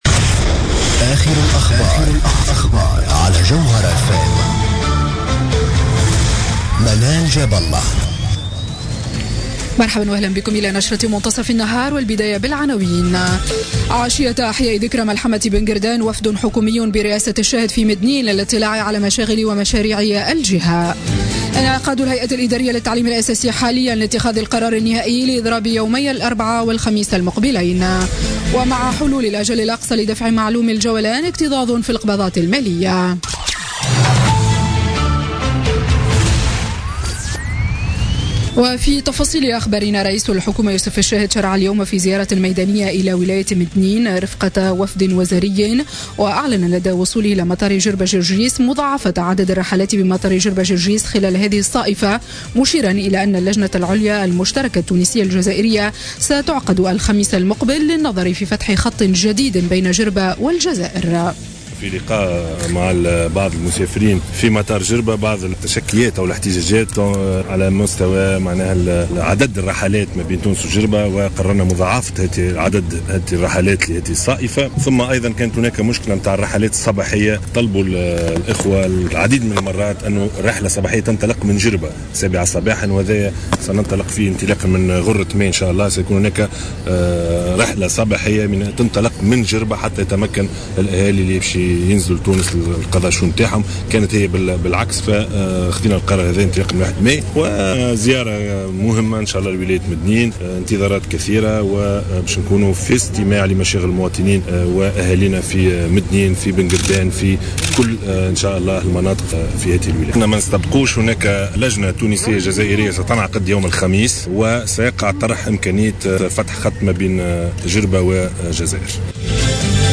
نشرة أخبار منتصف النهار ليوم الإثنين 6 مارس 2017